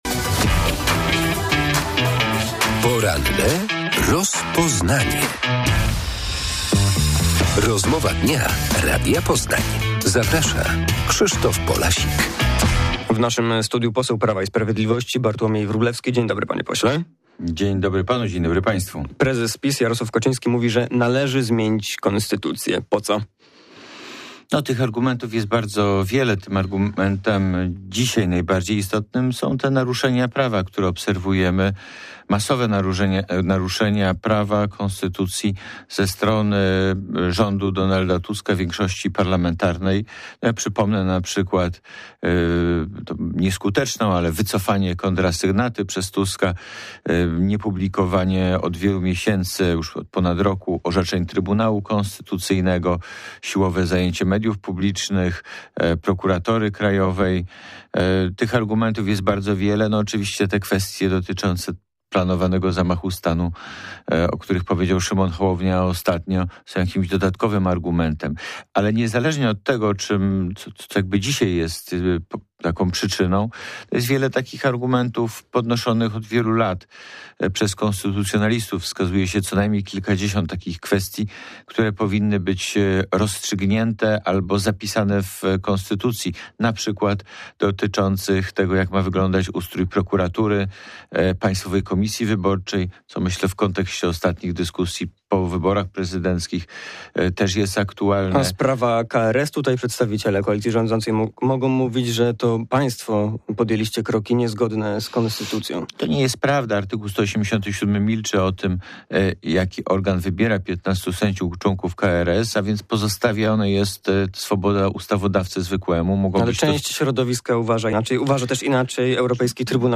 Prezes Prawa i Sprawiedliwości Jarosław Kaczyński zapowiedział starania o zmianę Konstytucji oraz wewnętrzne wybory w partii. Gościem rozmowy jest poseł PiS Bartłomiej Wróblewski